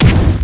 firehit1.mp3